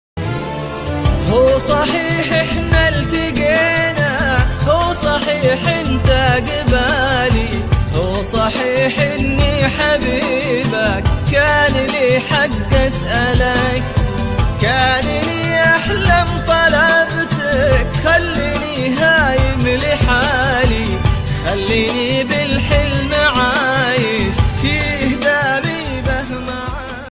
هي من أجمل الأغاني الرومانسية
بصوته العذب